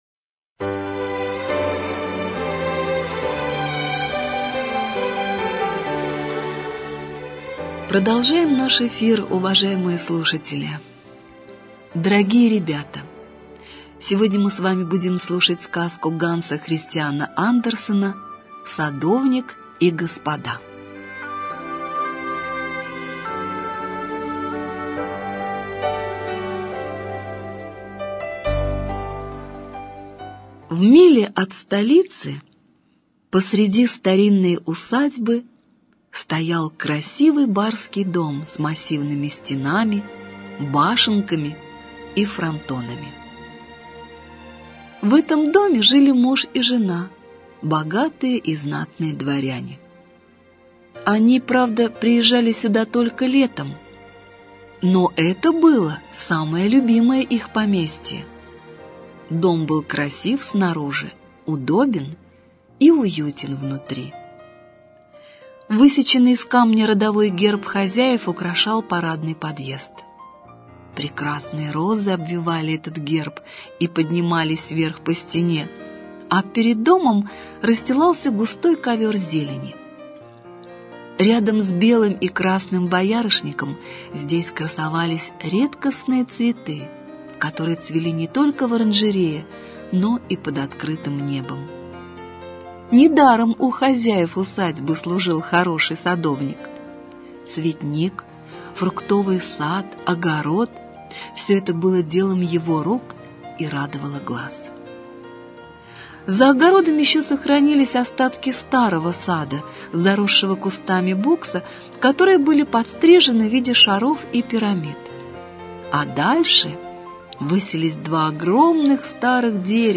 Садовник и господа - аудиосказка Андерсена. Сказка показывая значимость доброго слова, признания и похвалы.